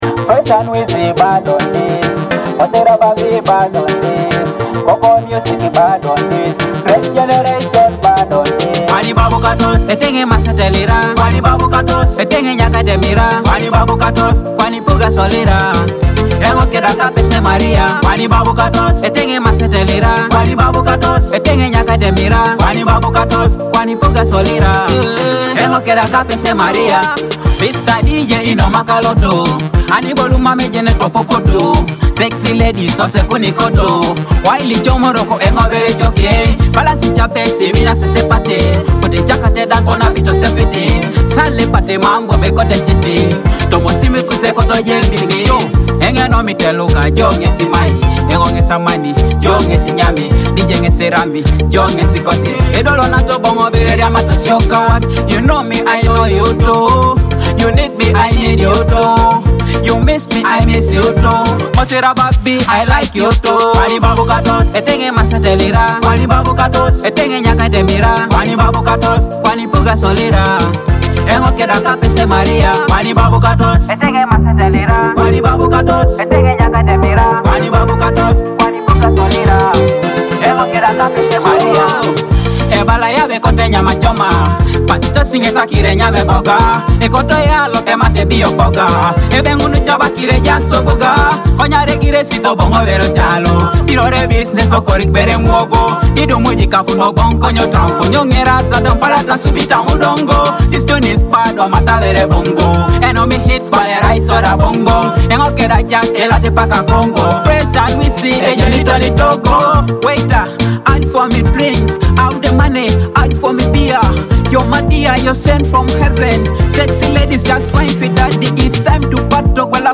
dancehall banger